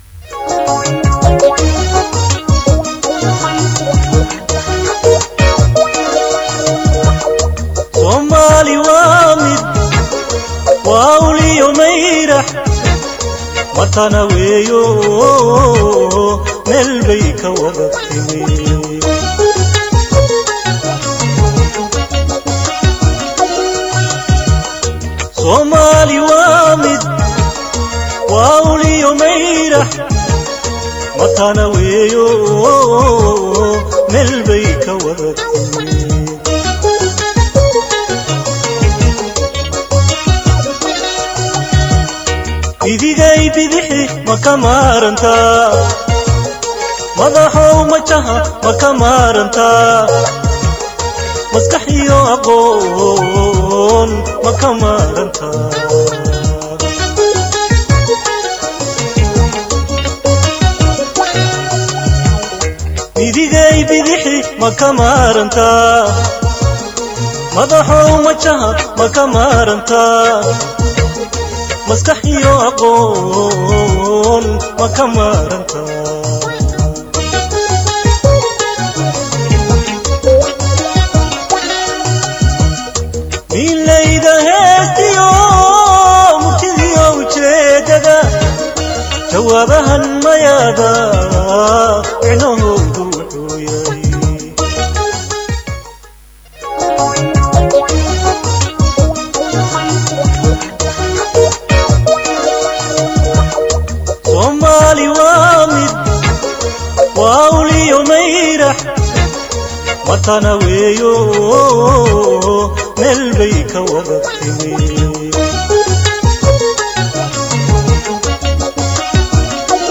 Barnaamijka faaqidaad wararka waxa uu si maalinle uga baxayaa Radio Muqdisho waxaana diirada lagu saaraa hadba dhacdooyinka ugu waa weyn